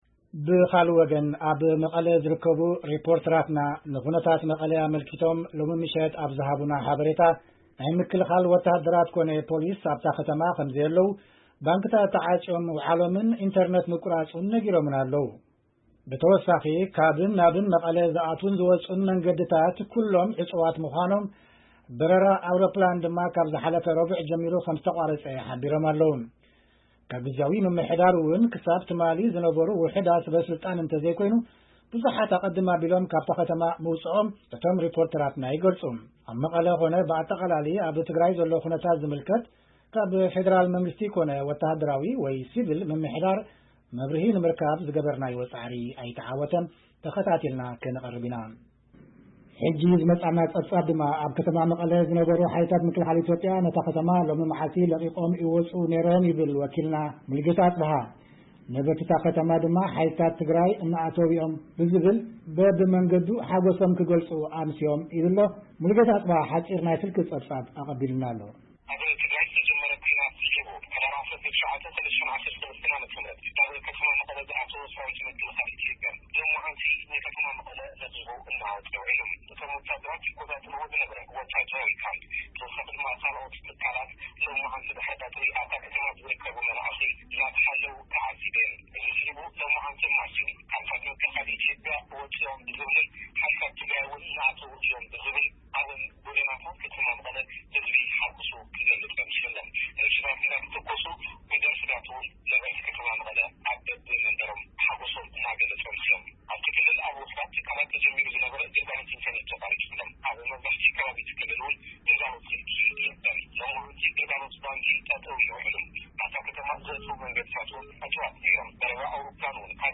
ንዘይጽሩይ ድምጺ ይቕሬታ ንሓትት!